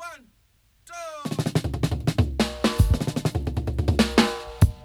136-FILL-DRY.wav